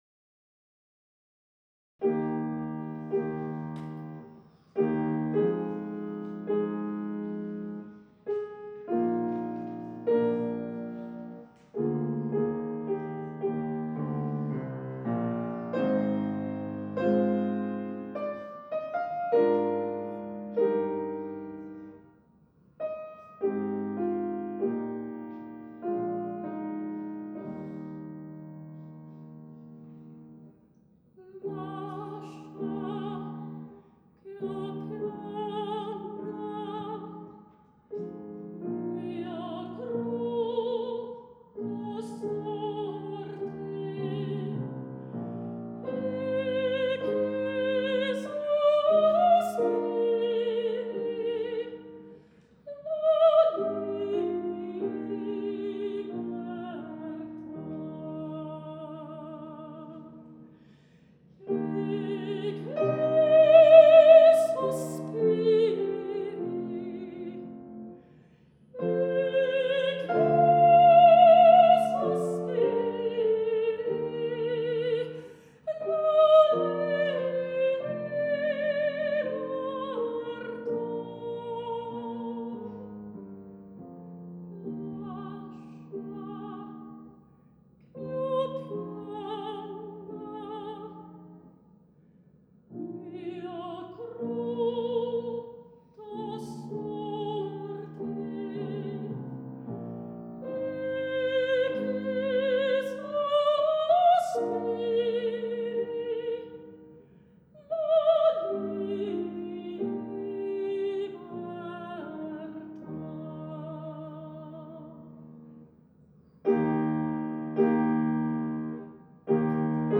Recital
In addition to art songs, arie antiche, or early (mostly) Italian arias, form part of any classical singer’s training.
This has been performed at Miriam Makeba Concert Hall, Unisa; Musaion, UP; DR Church Hall, Knysna